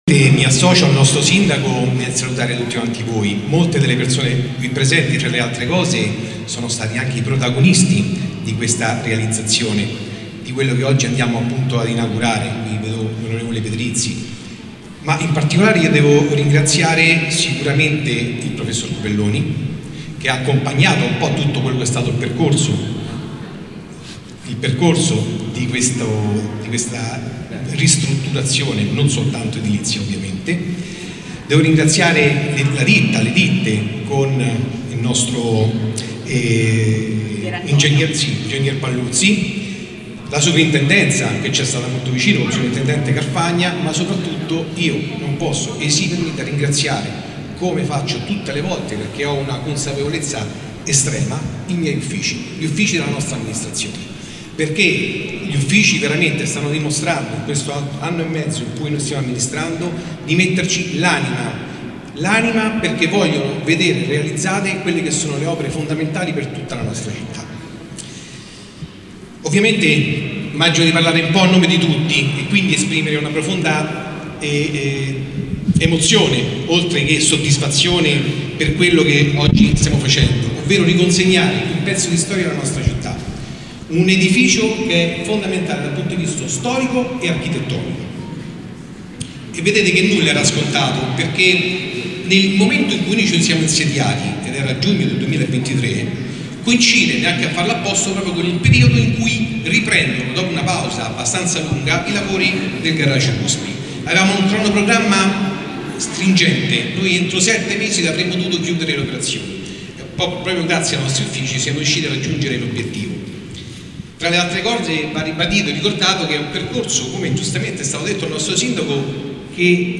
“Per raggiungere questo obiettivo è servita la continuità amministrativa, ma non è la semplice riqualificazione di un immobile. E’ rigenerazione e rigenerare vuol dire contrastare la desertificazione commerciale, dare più sicurezza, rilanciare tutto il quartiere”, è stato sottolineato dal vicesindaco e assessore ai lavori pubblici Massimiliano Carnevale